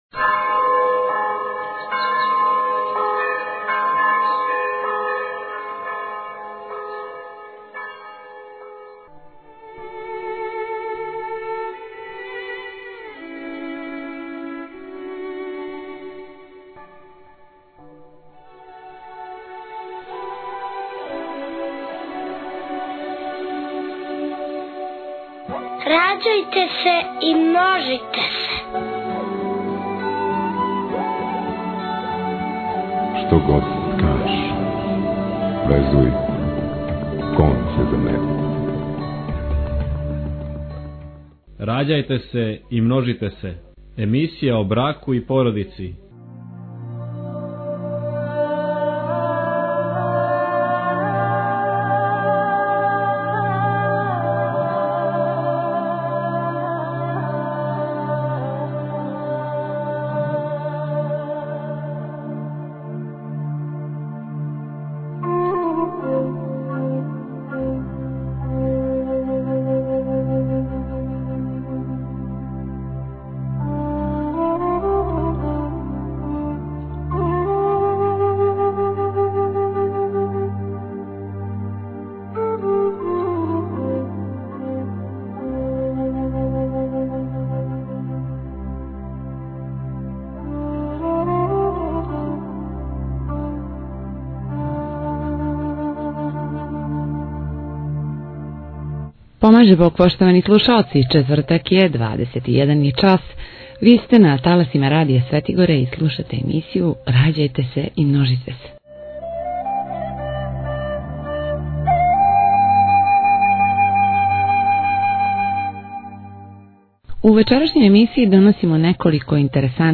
У вечерашњој емисији доносимо интервју